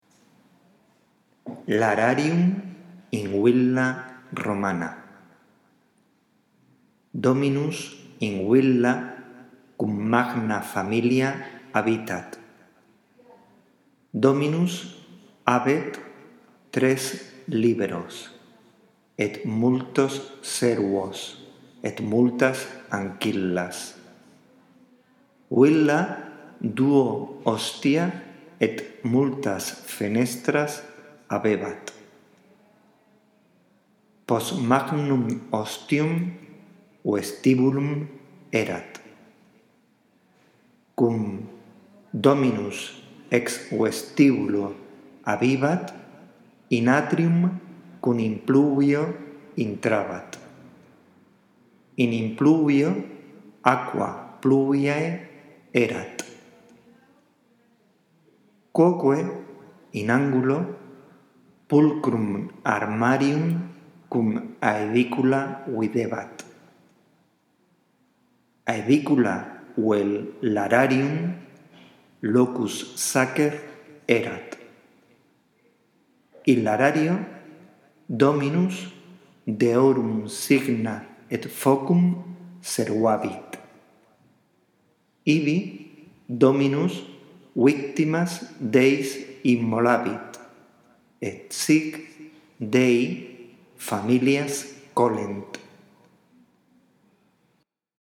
Esta grabación te ayudará en la práctica de la lectura del texto latino